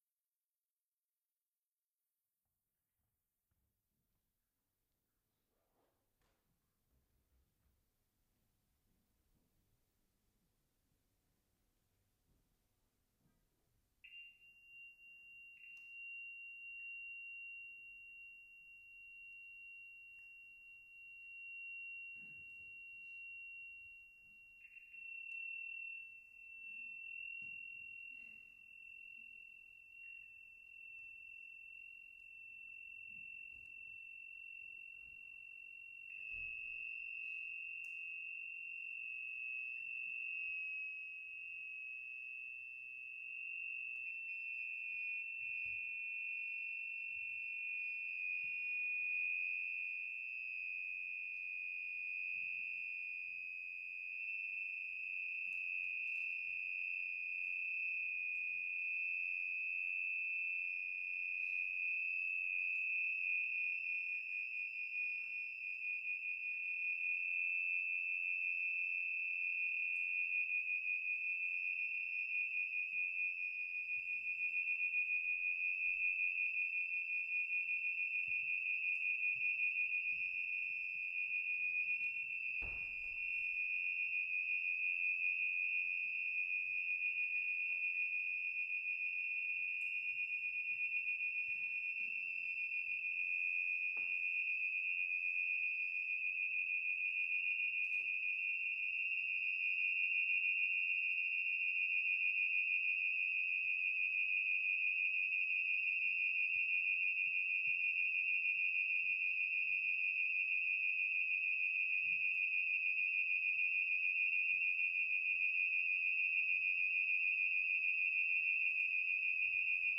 A collaborative duo performance
drummer